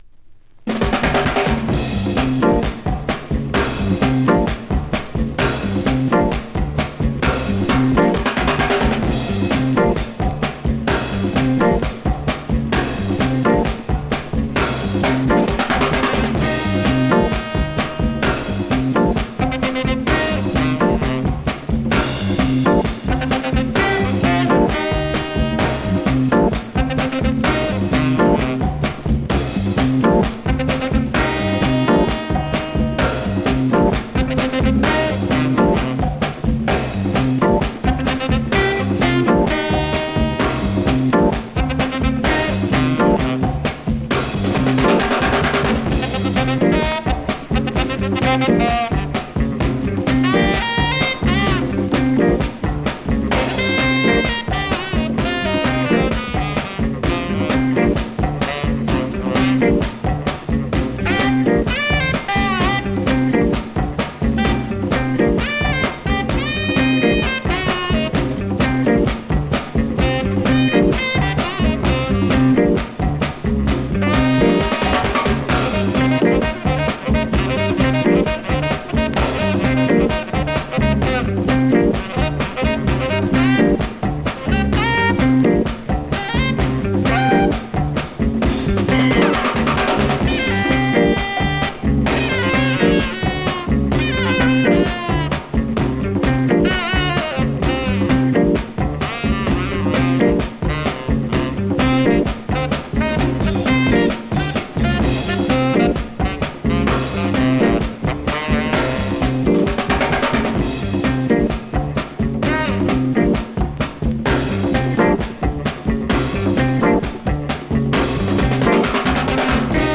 Vinyl has a few light marks plays great .
Great classic up-tempo Mod / Funk Instro dancer .